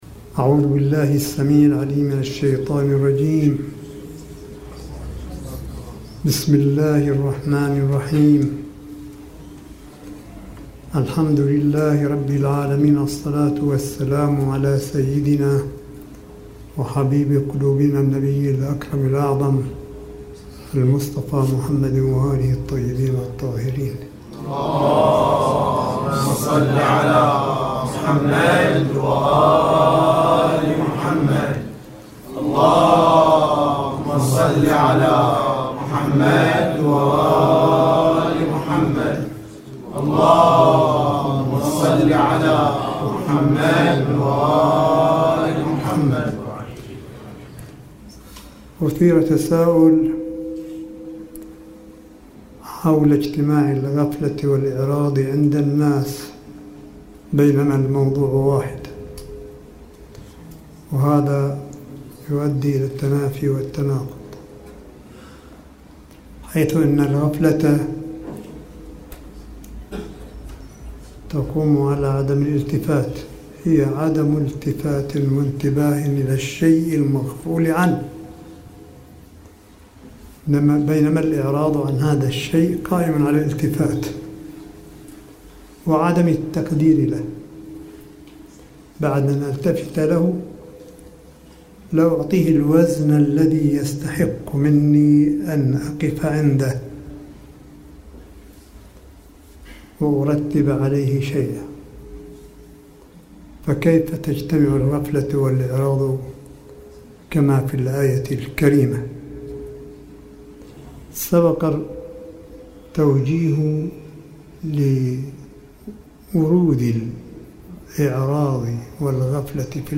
ملف صوتي للحديث القرآني لسماحة آية الله الشيخ عيسى أحمد قاسم حفظه الله بقم المقدسة – 6 شهر رمضان 1440 هـ / 12 مايو 2019م